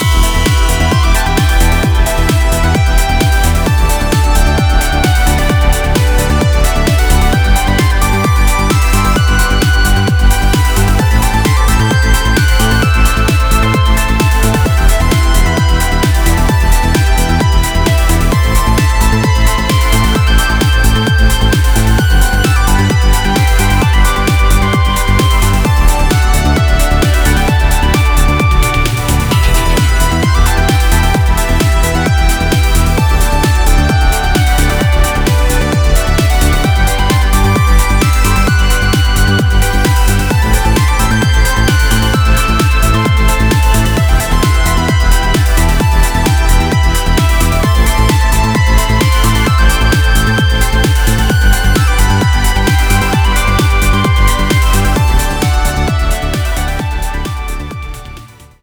Trance - L.....